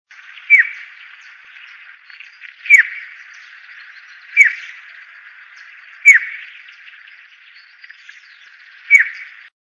Urraca Común (Cyanocorax chrysops)
Nombre en inglés: Plush-crested Jay
Localidad o área protegida: Reserva Ecológica Costanera Sur (RECS)
Condición: Silvestre
Certeza: Vocalización Grabada